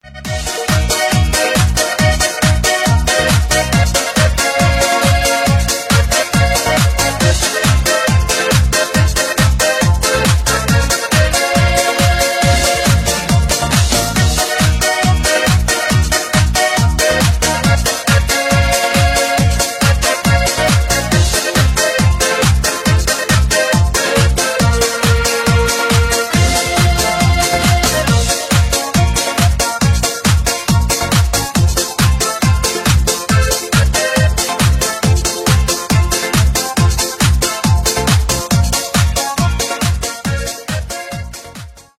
2020 » Без Слов » Русские » Танцевальные Скачать припев